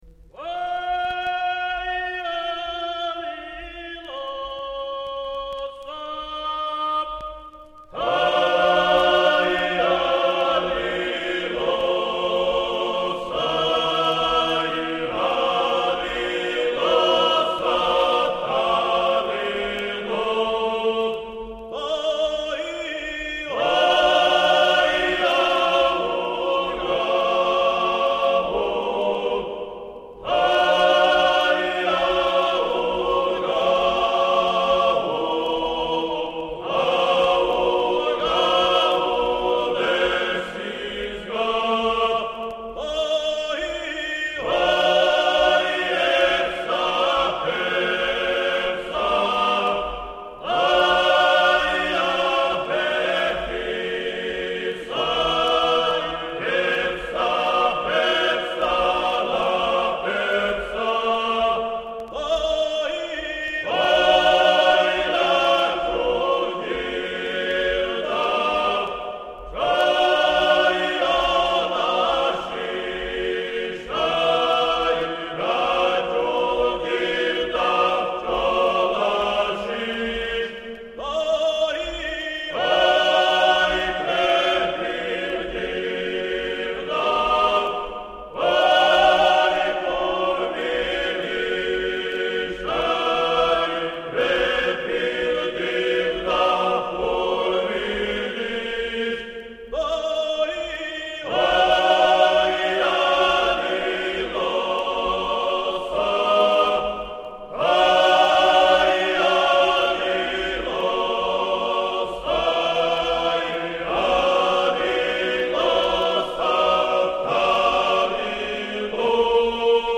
Christmas carols
Keywords: ქართული ხალხური სიმღერა